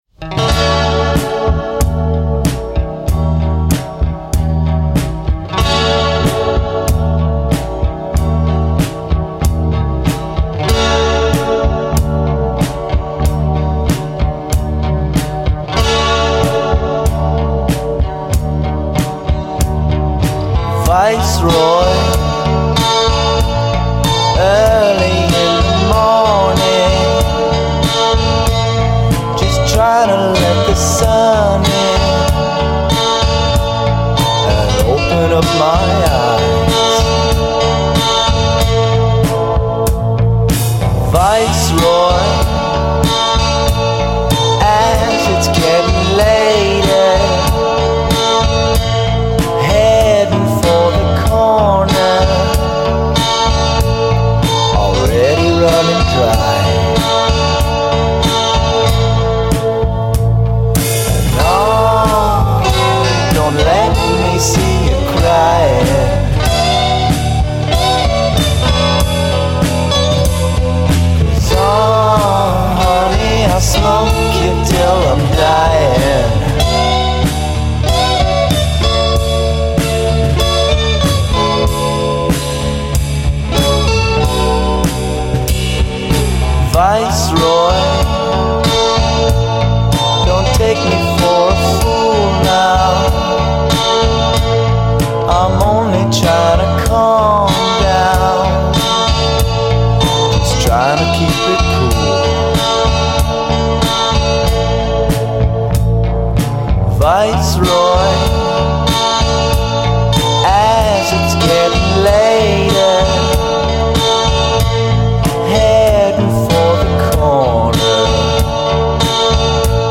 It’s slacker rock, basically, full of beachy surf guitars.